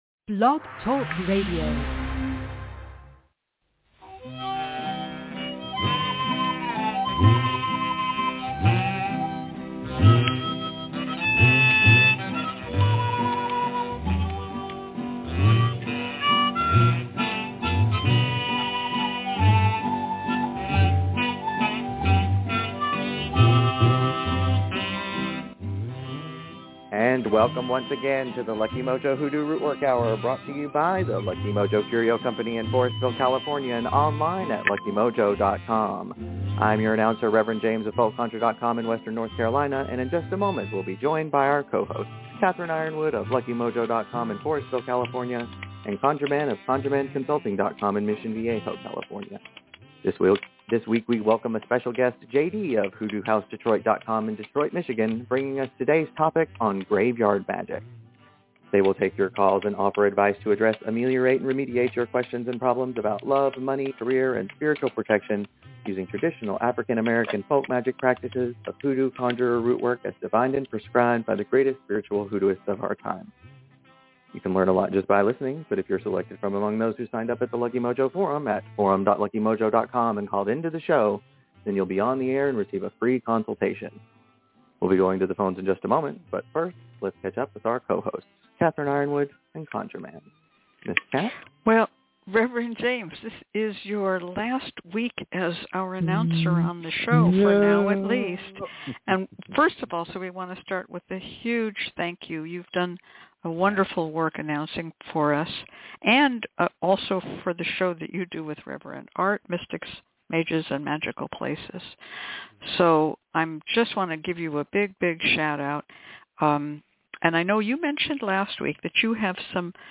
Plus: Listener call-ins, announcements about upcoming shows, and insights from experienced rootworkers on traditional hoodoo practices.